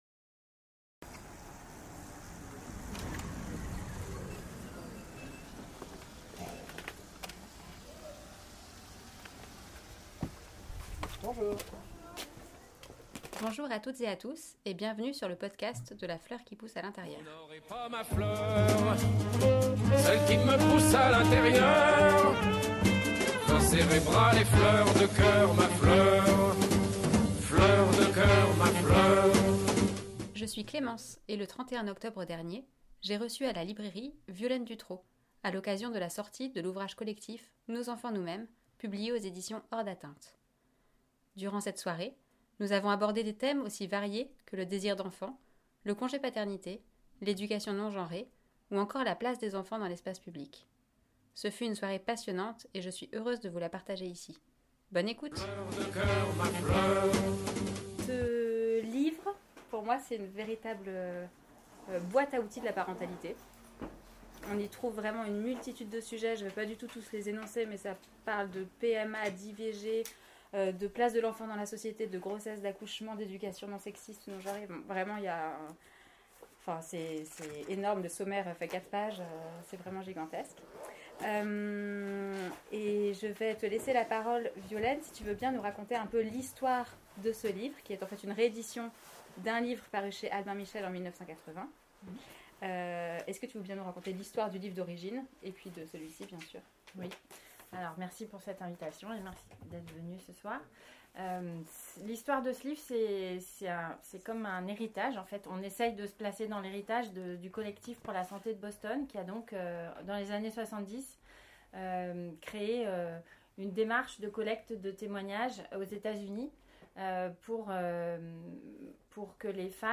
Dans la lignée de « Notre corps nous-mêmes », le livre « nos enfants nous-mêmes » est un manuel de parentalité construit à partir de témoignages. Un membre du collectif présente son contenu.